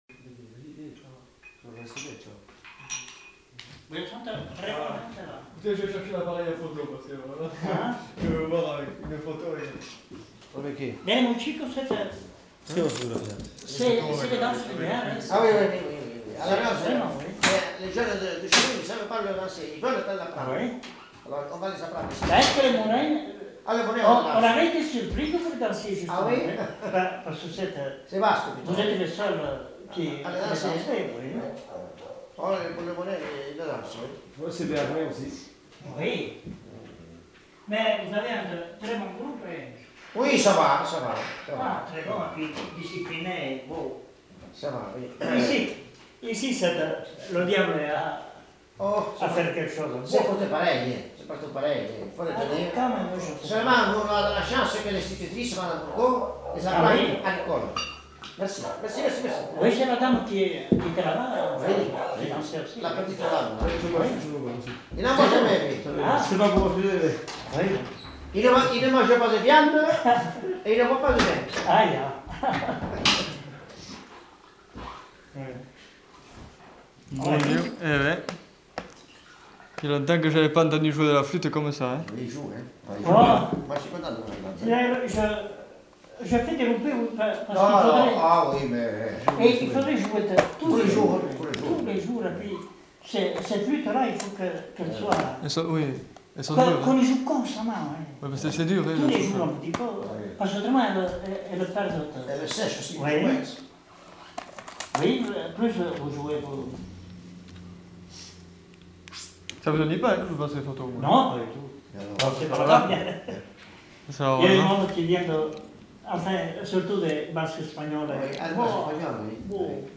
Discussion à bâtons rompus
Aire culturelle : Pays Basque
Lieu : Trois-Villes
Genre : parole